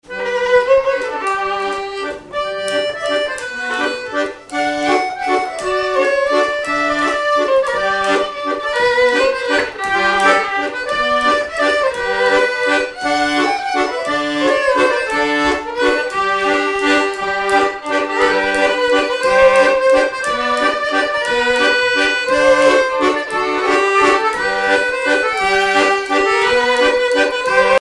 danse : valse
violon
Pièce musicale inédite